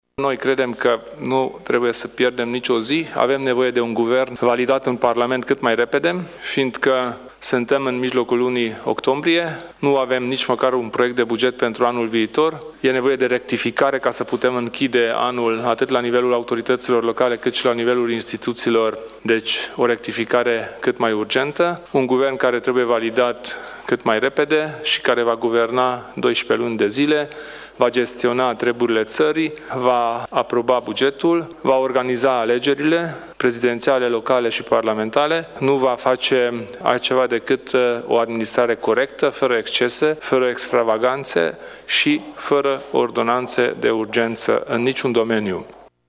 Kelemen Hunor a vorbit despre nevoia de a se adopta bugetul şi de o rectificare bugetară:
stiri-11-oct-declaratii-UDMR.mp3